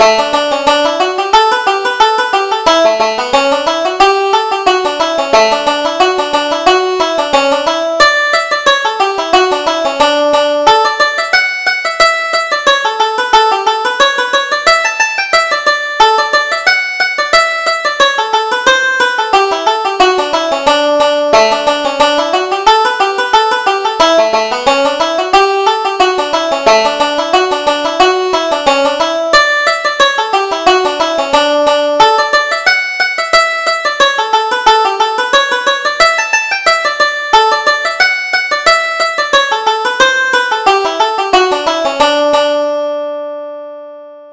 Reel: The Jug of Punch